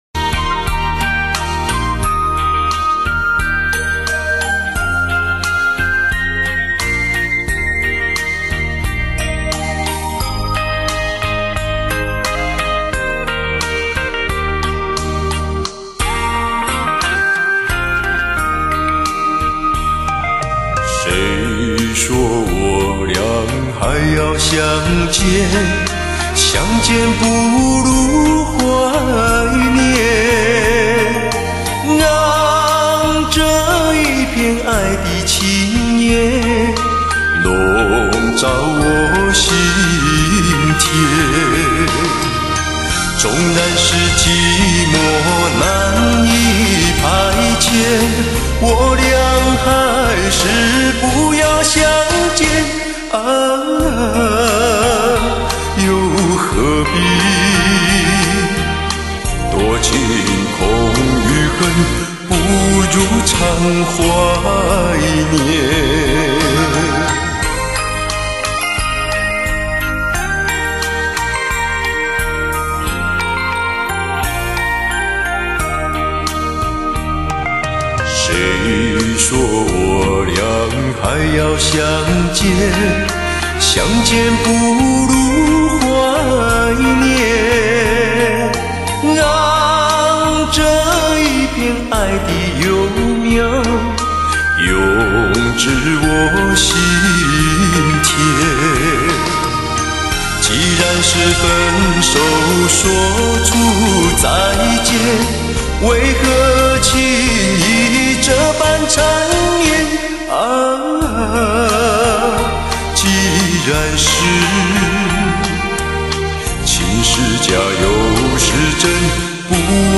发烧天碟，经典回放，倾情演绎动人老歌
传奇真空管录音5.1DTS CD 经典珍藏
傳奇真空管錄音，美國DTS-5.1頂級編碼器，還原現場震撼體驗
發燒極品，百聽不厭;全頻段六聲道製作如同置身音樂聽之中。
傳奇真空管處理，頂級音效環繞體！